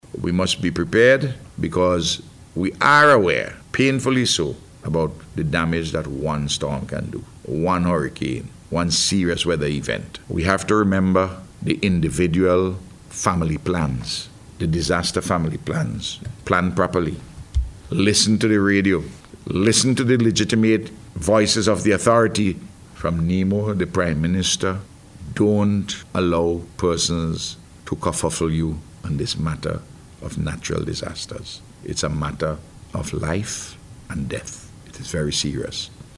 In a radio address to mark the start of the Season, the Prime Minister urged persons to remember the relevant natural disaster plans and to listen to the radio for accurate information from local authorities.